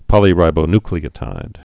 (pŏlē-rībō-nklē-ə-tīd, -ny-)